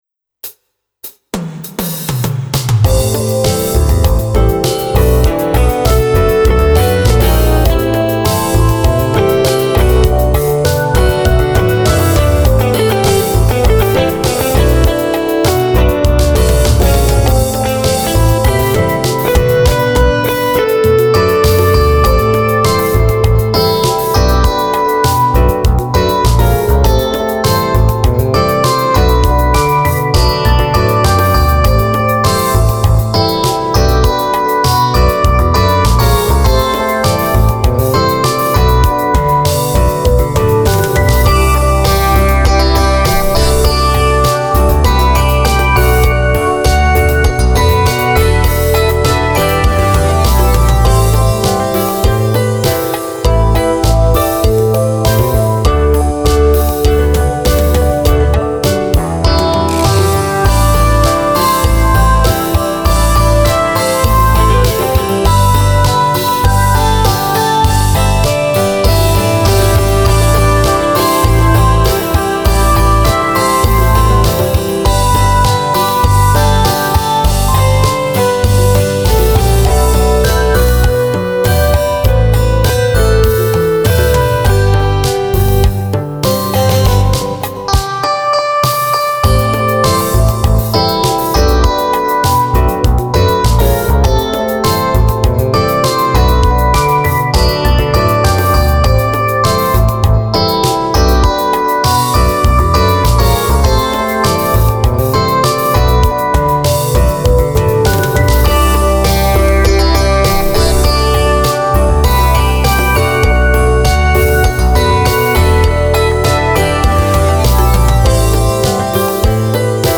歌ものとして書き始めたが歌詞が伴わないうちに完成せてしまったため、とりあえずインストとして公表。
ギターをギターらしくかけてきた頃の作品。